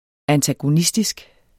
Udtale [ antagoˈnisdisg ]